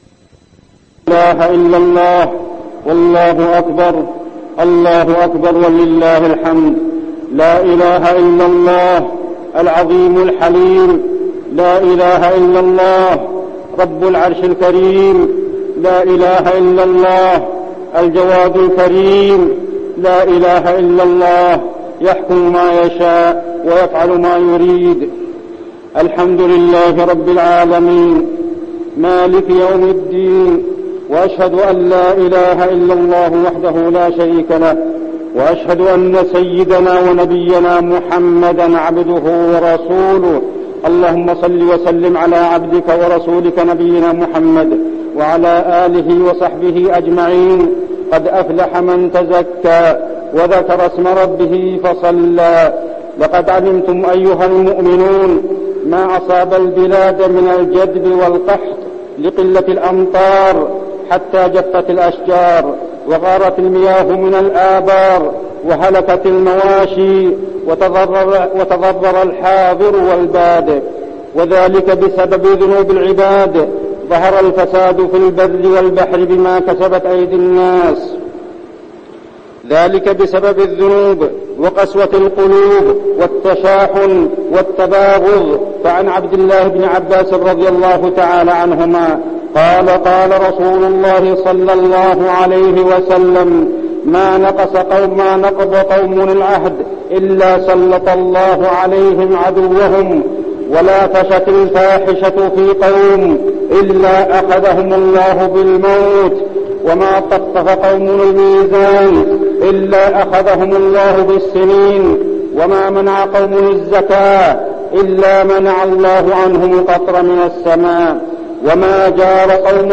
خطبة الاستسقاء
المكان: المسجد النبوي